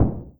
EXPLOSION_Subtle_Muffled_stereo.wav